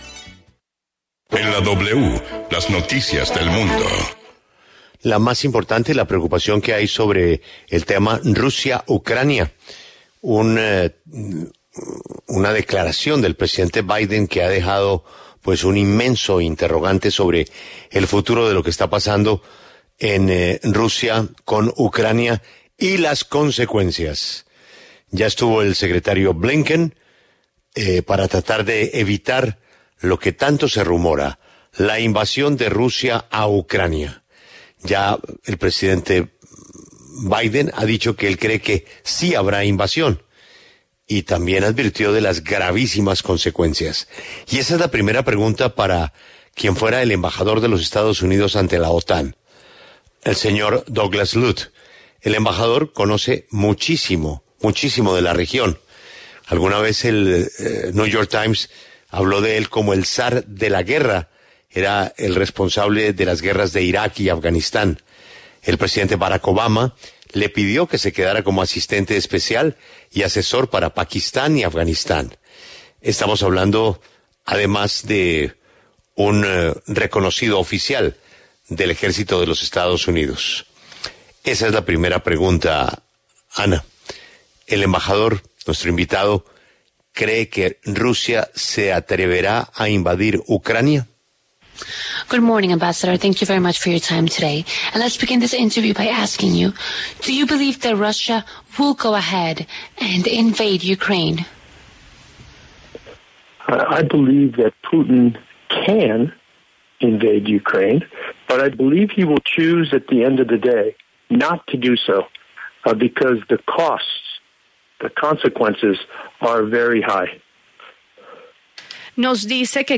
Douglas Lute, exembajador de Estados Unidos en la OTAN desde 2013 a 2017, habló en La W sobre los alcances de la reunión de Antony Blinken con Rusia y los alcances de la presencia de militares en la frontera con Ucrania.